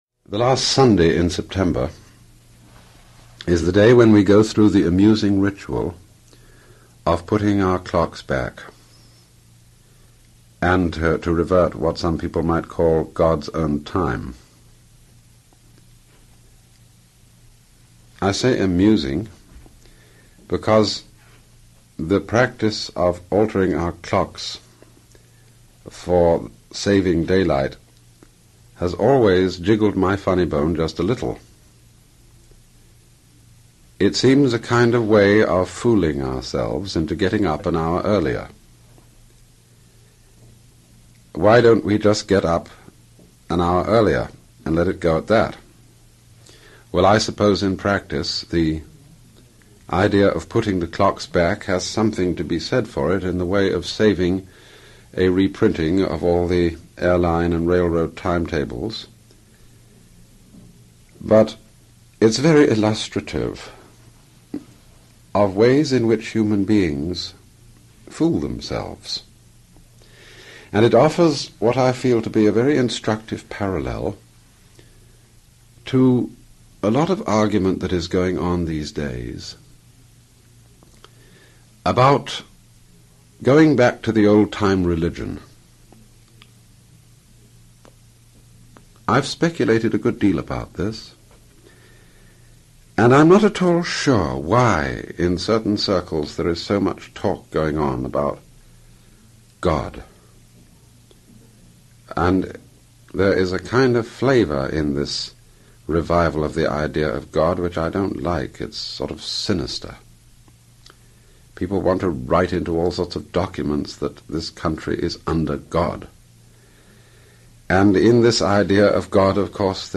alan-watts-early-radio-talks-07-daylight-savings-time-and-god